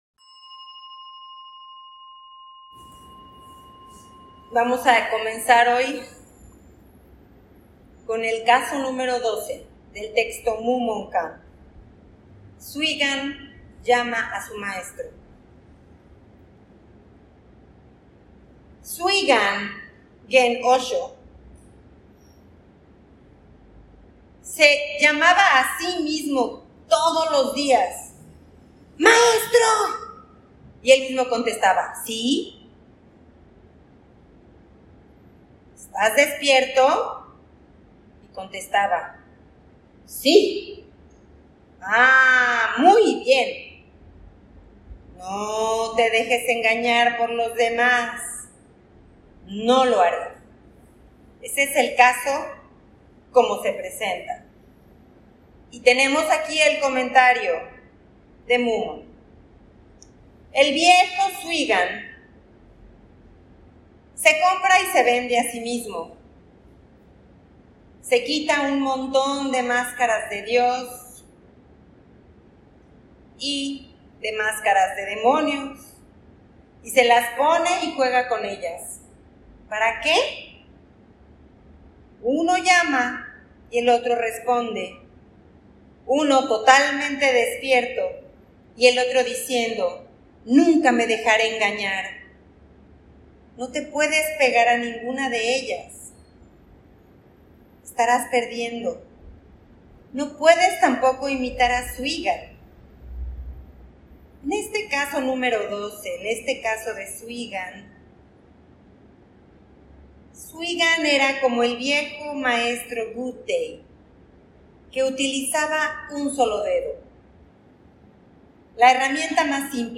Copia de Teisho - Canción Hakuin Zenji | One Drop Zen México